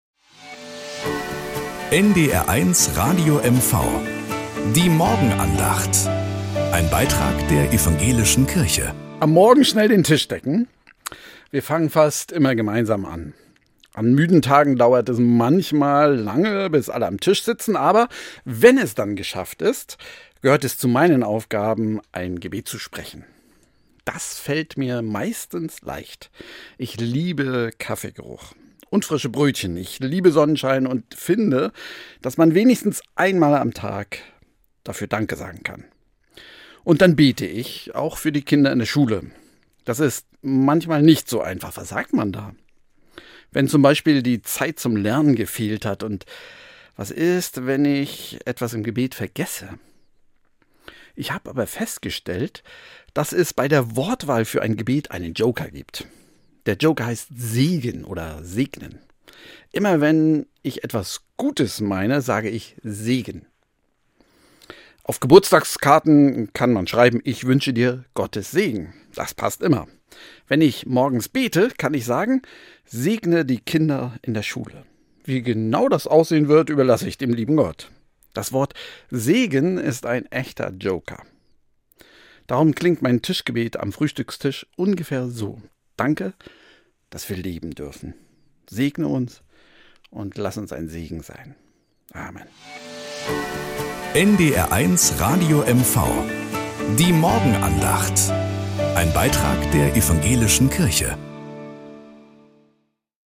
Morgenandacht bei NDR 1 Radio MV
Um 6:20 Uhr gibt es in der Sendung "Der Frühstücksclub" eine
dabei ab. Am Montag auf Plattdeutsch.